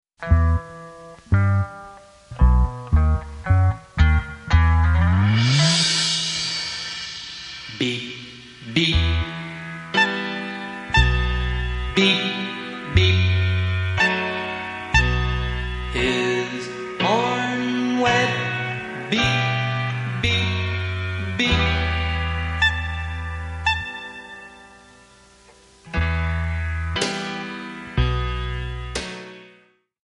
Em
MPEG 1 Layer 3 (Stereo)
Backing track Karaoke
Pop, Oldies, 1950s